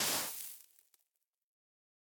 Minecraft Version Minecraft Version 25w18a Latest Release | Latest Snapshot 25w18a / assets / minecraft / sounds / item / brush / brush_sand_complete3.ogg Compare With Compare With Latest Release | Latest Snapshot
brush_sand_complete3.ogg